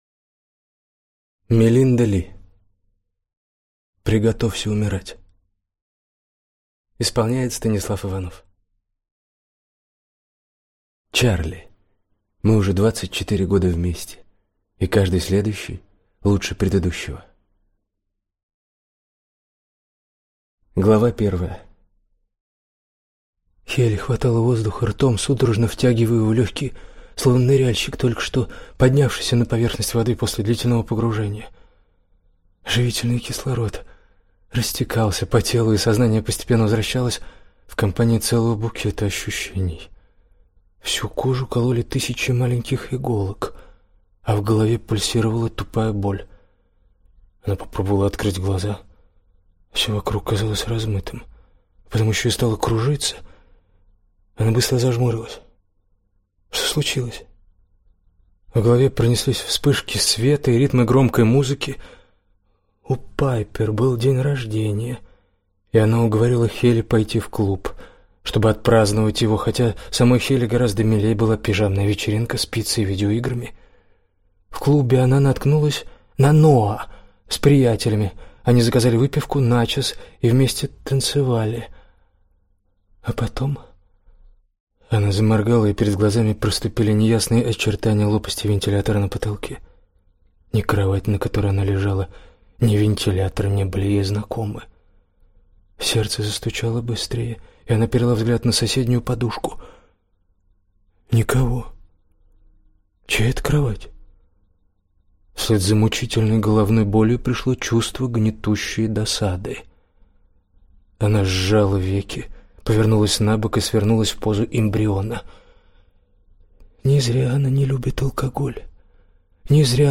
Аудиокнига Приготовься умирать | Библиотека аудиокниг